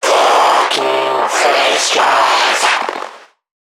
NPC_Creatures_Vocalisations_Infected [122].wav